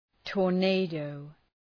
Προφορά
{tɔ:r’neıdəʋ}